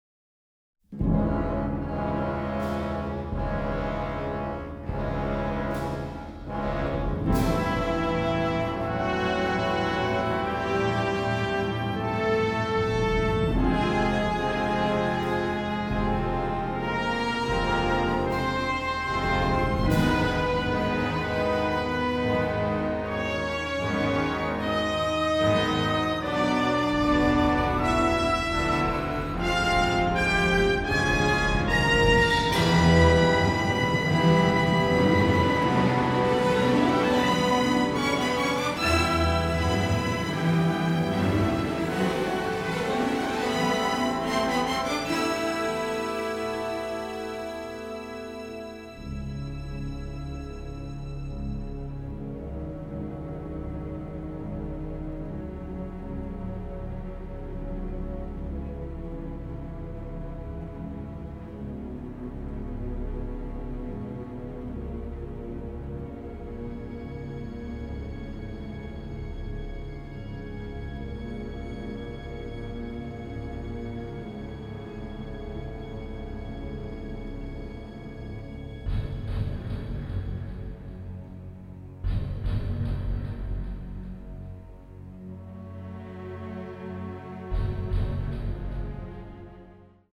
contemporary electronics